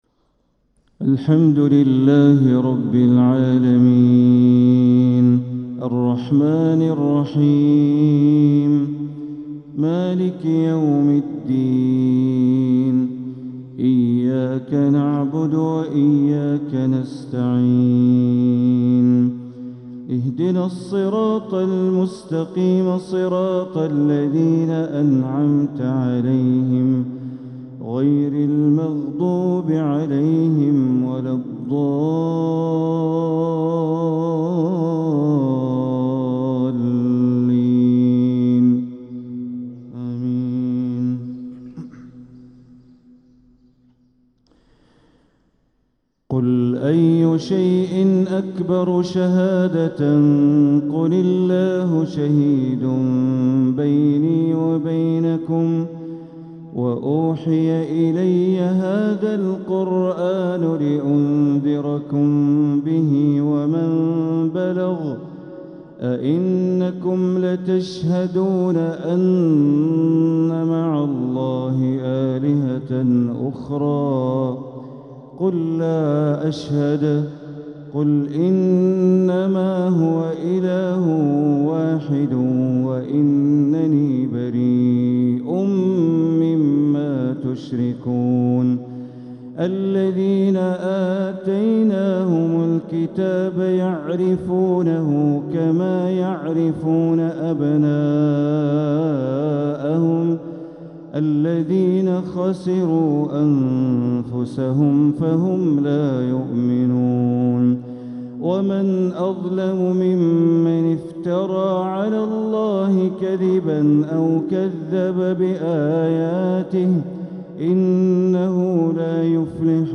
تلاوة من سورة الأنعام ١٩-٣٦ | فجر الإثنين ١٤ ربيع الآخر ١٤٤٧ > 1447هـ > الفروض - تلاوات بندر بليلة